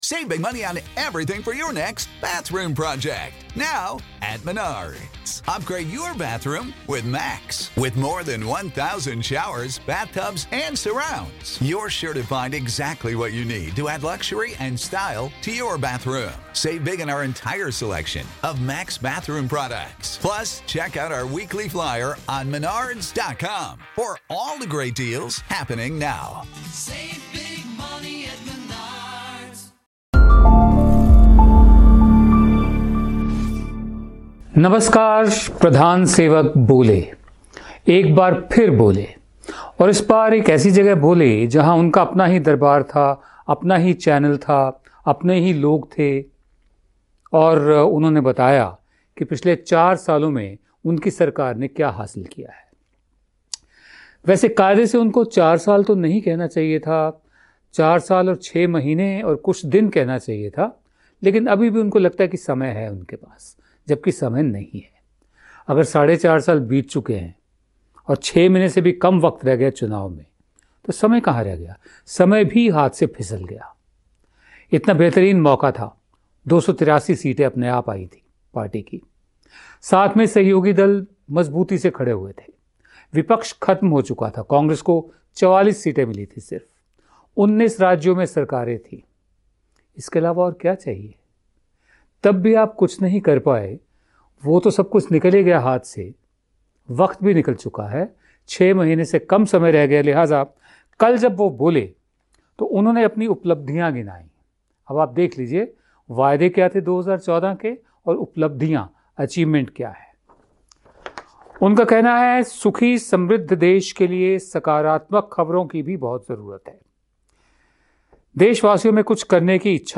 Our Political Analyst Mr. Vinod Dua gives honest insight and take as to what has been conspiring in the Indian economy so far.